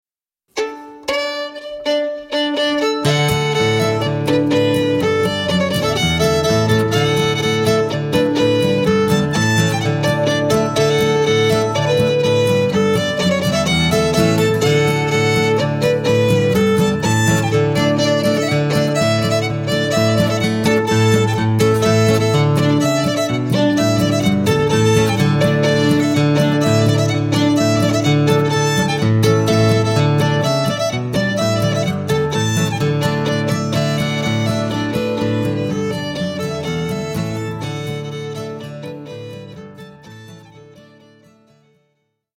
polka
fiddle & guitar duo
Scots-Irish & Americana